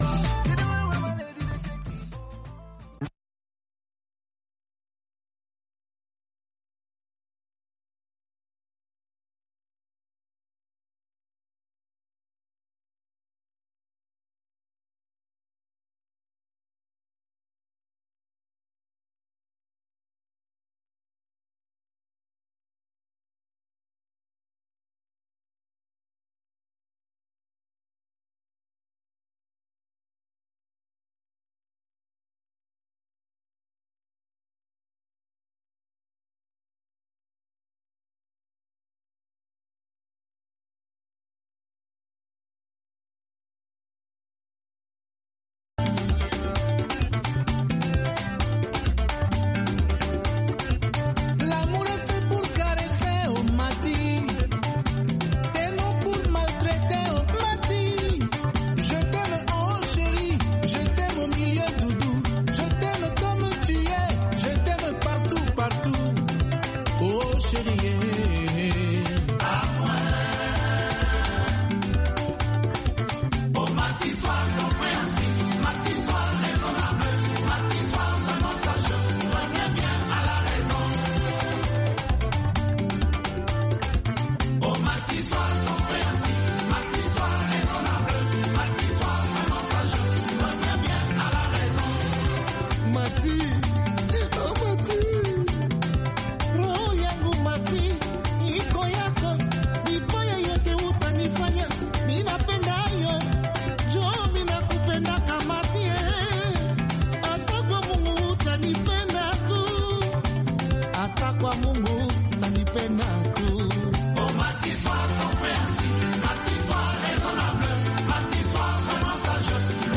Kullum da karfe 6 na safe agogon Najeriya da Nijar muna gabatar da labarai da rahotanni da dumi-duminsu, sannan mu na gabatar da wasu shirye-shirye kamar Noma da Lafiya Uwar Jiki.